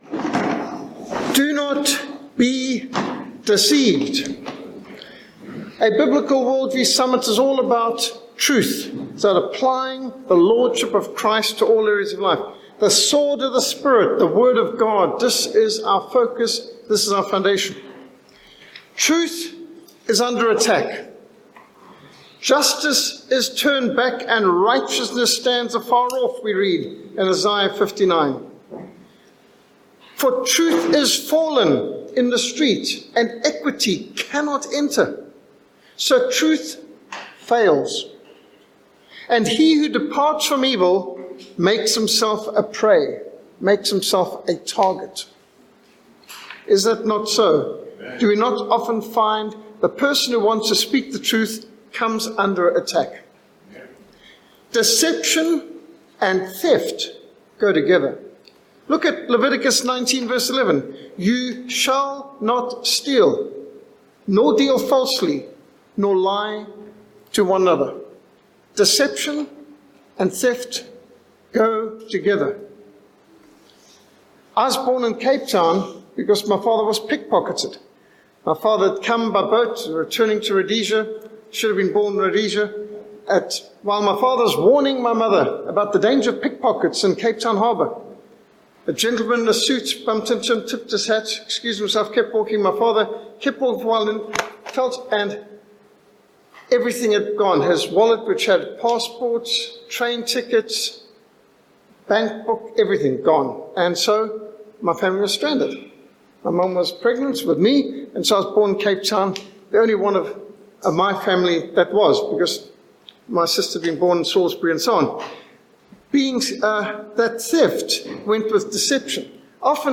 Do Not Be Deceived | SermonAudio Broadcaster is Live View the Live Stream Share this sermon Disabled by adblocker Copy URL Copied!